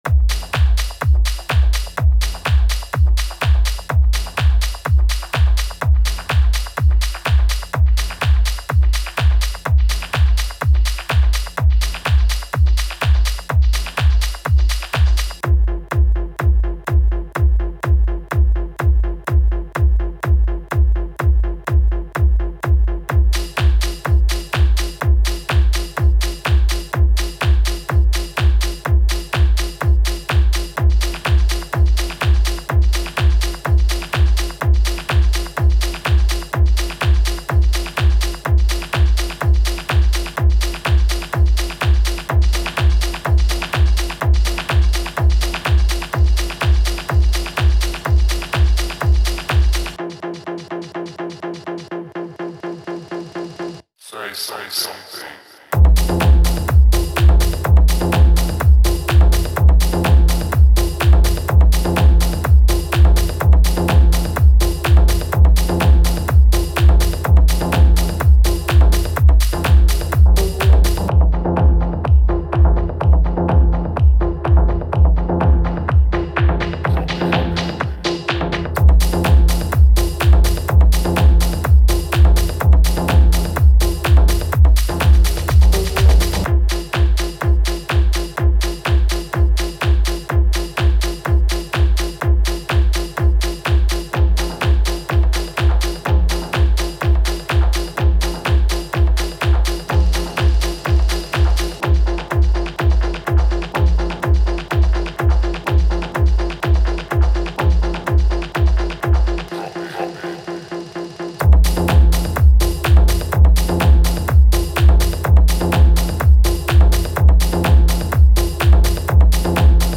Genre Tech House BPM 125
Club Mix